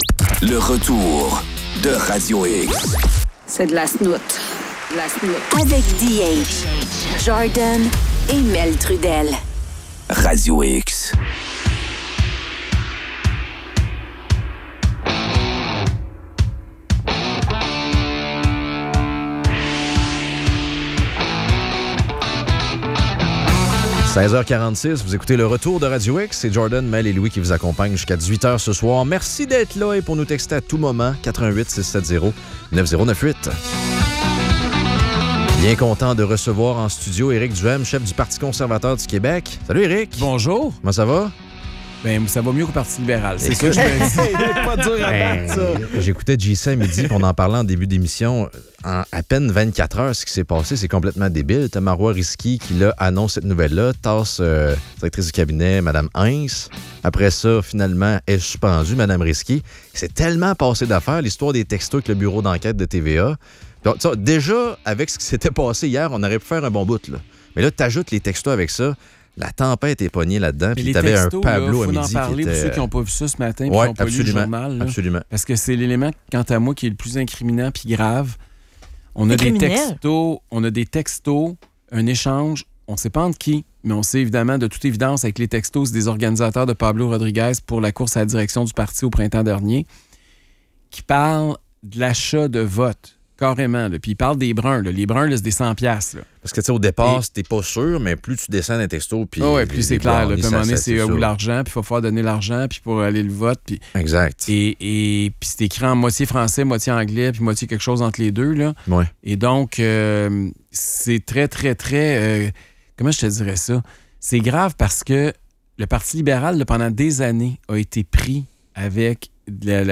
Entrevue avec Éric Duhaime.